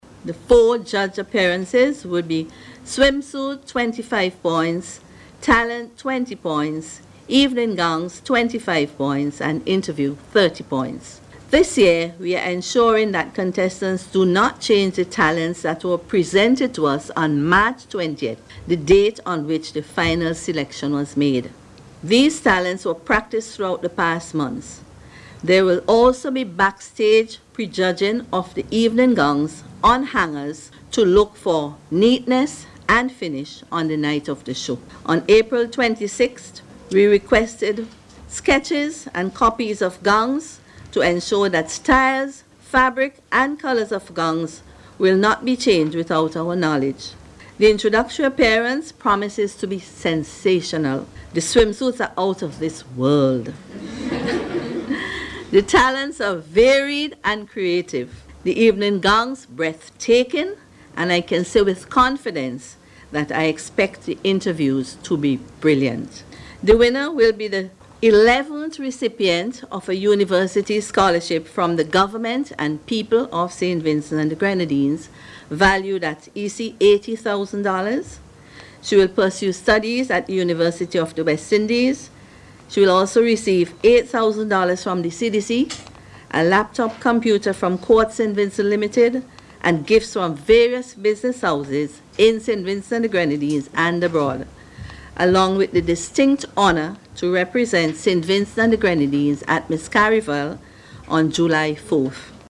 at a News Conference hosted by the CDC on Tuesday.